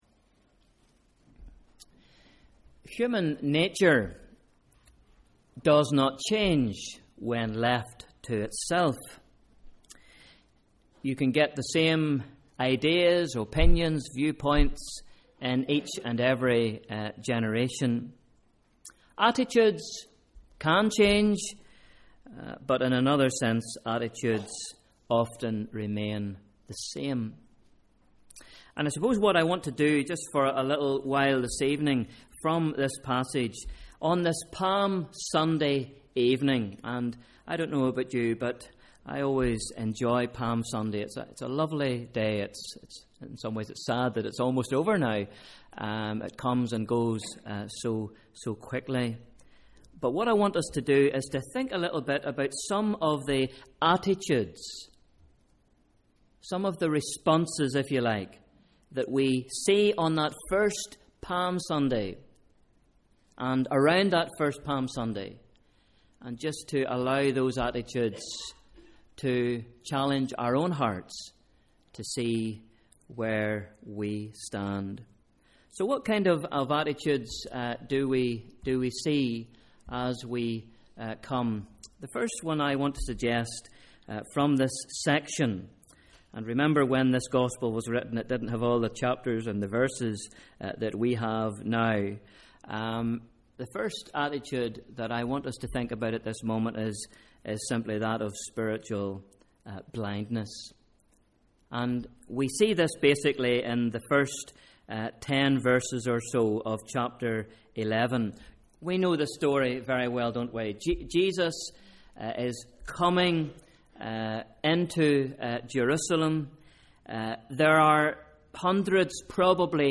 BIBLE READING: Mark 10 v 46 — 11 v 19 Sunday Evening Service